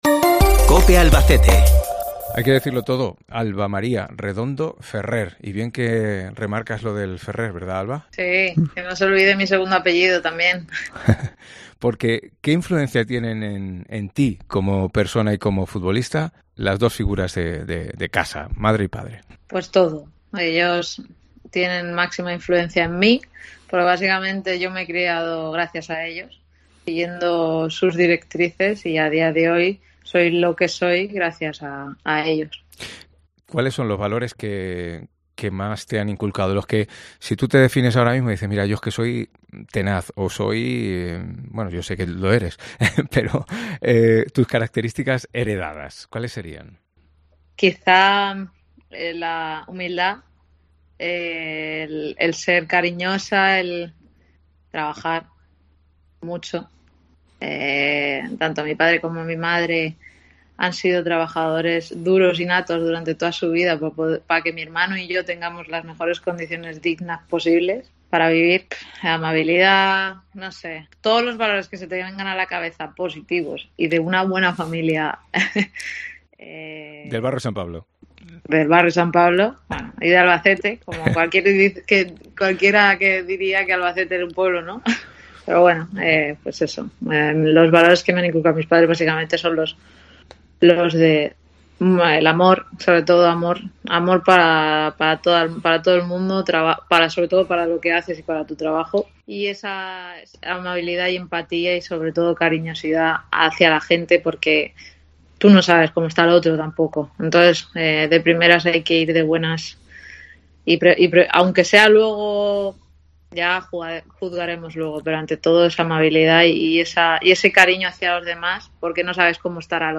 La campeona del Mundo habla